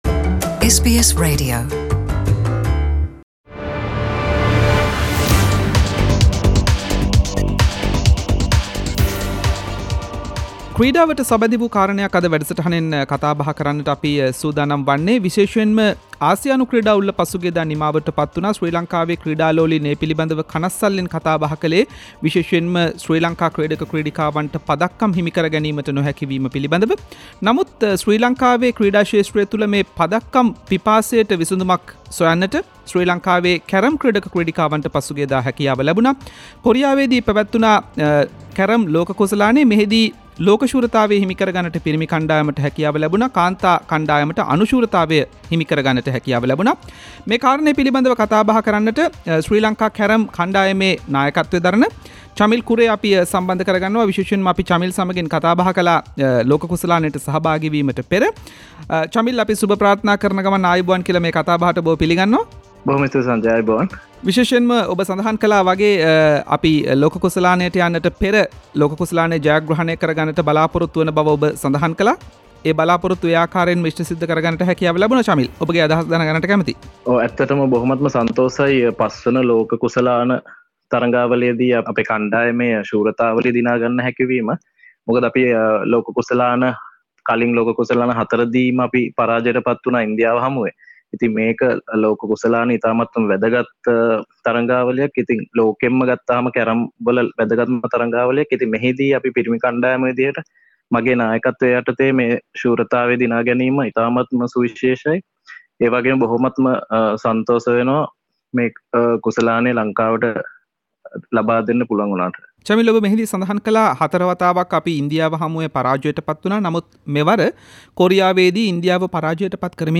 Special interview